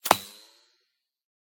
whine_11.ogg